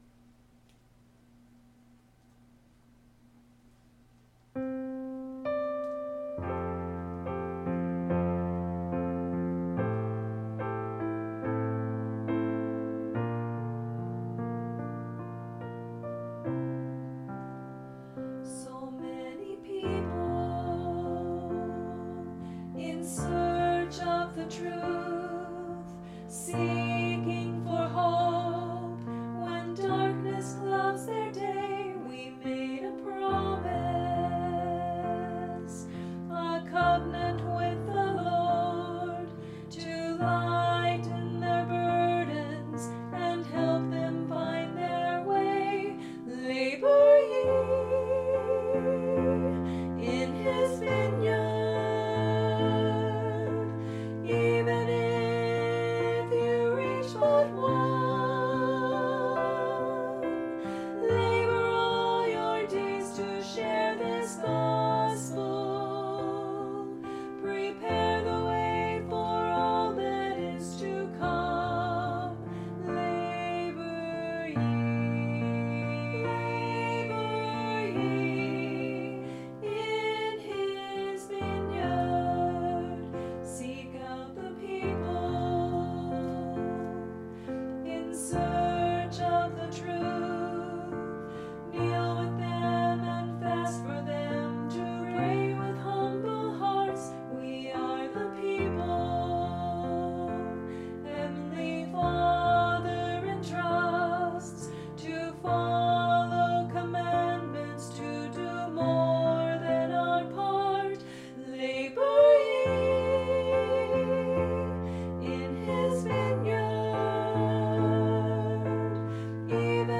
Duet, SA
Voicing/Instrumentation: SA , Duet